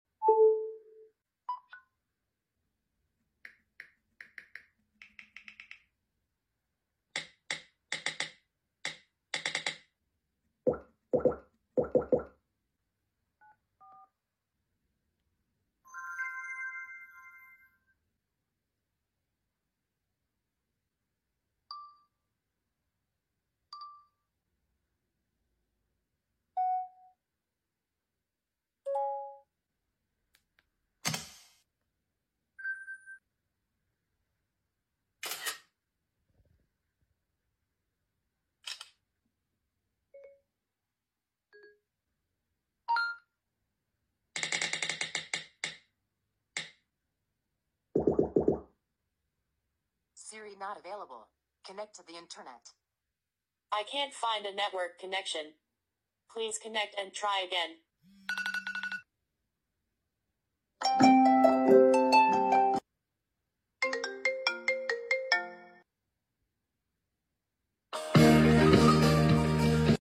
iPhone 5S and Samsung Galaxy S4 Sound Comparison